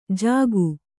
♪ jāgu